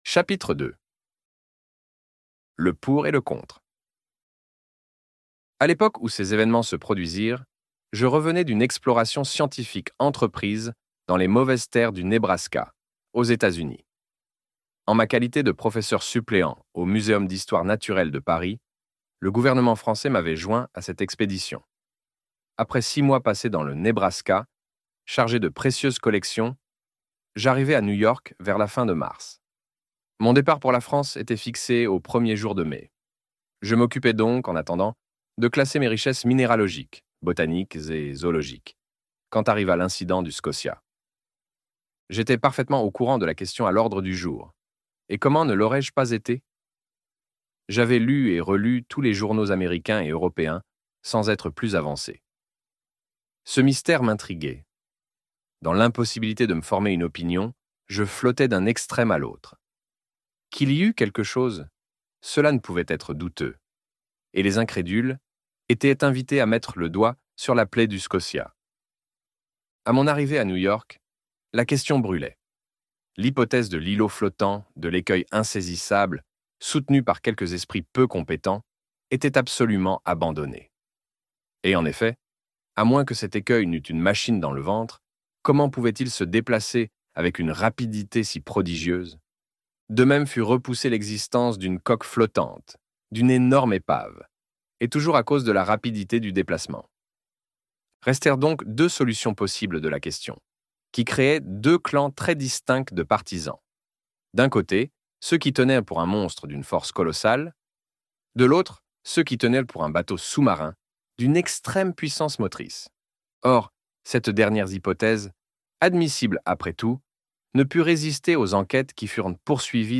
Vingt mille lieues sous les mers - Livre Audio